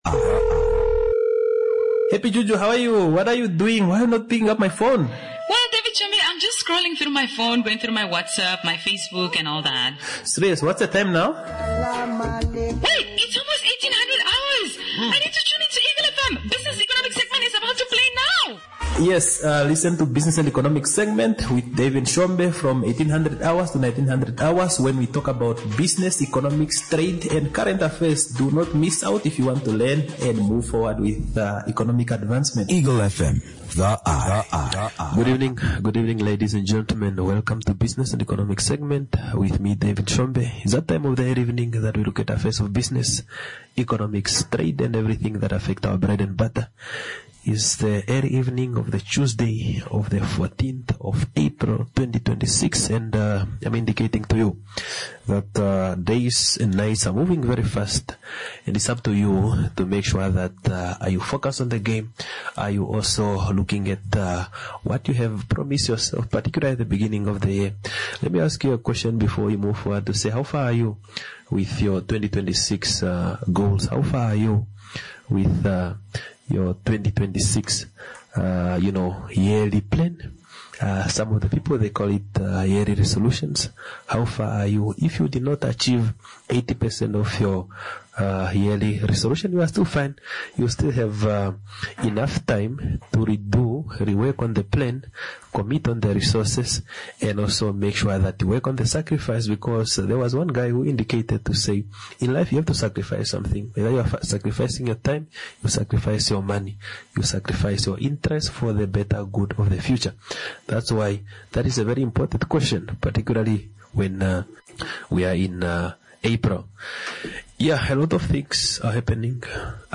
This discussion explores the growth of art and culture in Namibia, how young talent in the art industry can be identified, and the type of support artists